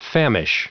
Prononciation du mot famish en anglais (fichier audio)
Prononciation du mot : famish